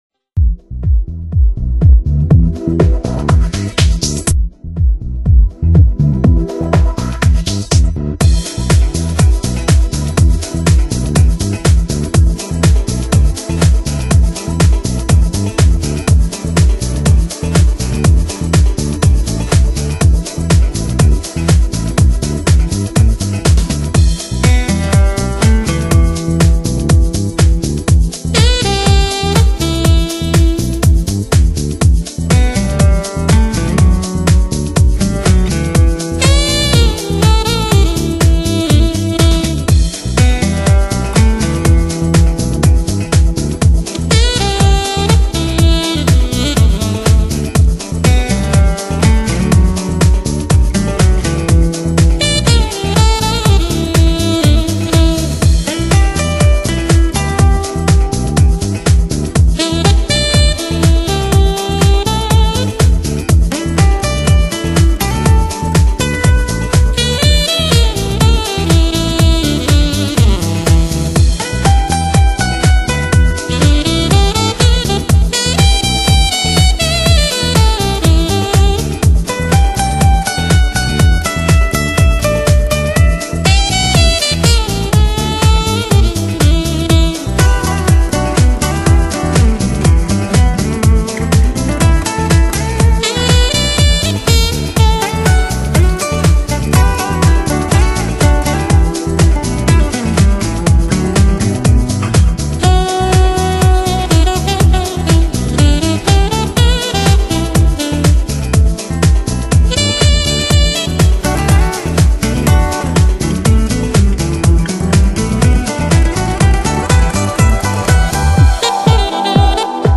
Genre: Deep House, Downtempo, Jazz
Stereo